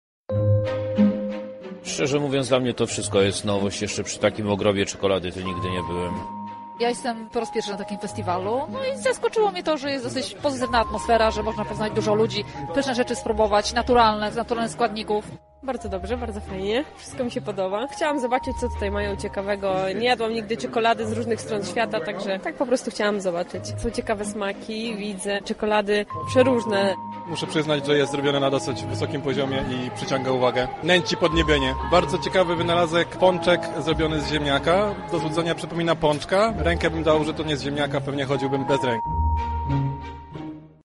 Część uczestników była zaskoczona ilością słodkich wyrobów: